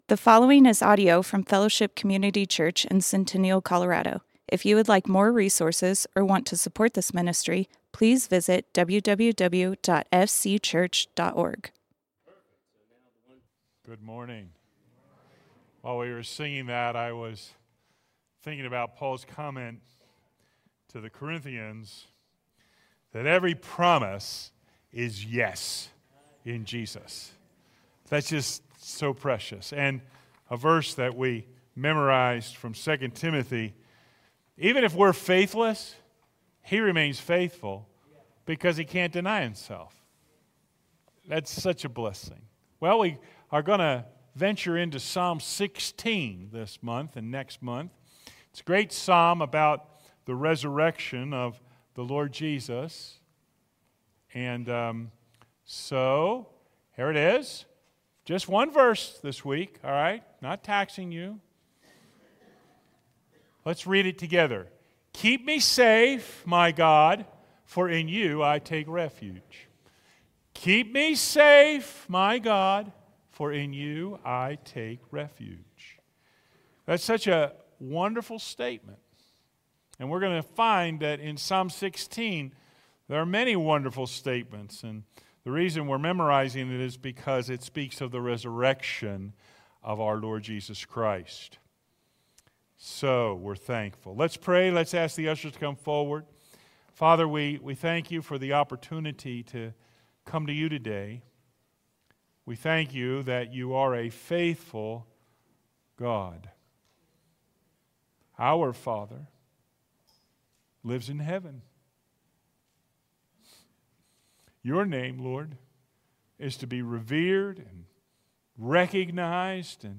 Fellowship Community Church - Sermons